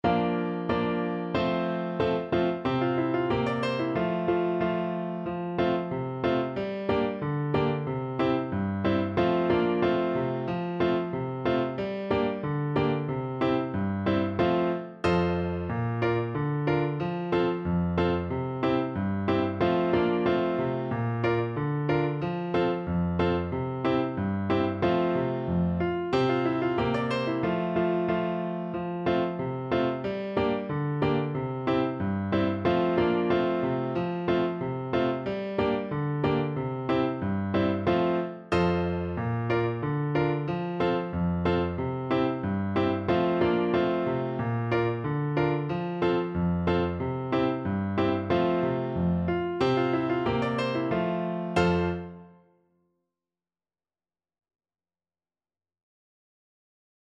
Play (or use space bar on your keyboard) Pause Music Playalong - Piano Accompaniment Playalong Band Accompaniment not yet available reset tempo print settings full screen
F major (Sounding Pitch) C major (French Horn in F) (View more F major Music for French Horn )
Jolly =c.92
2/2 (View more 2/2 Music)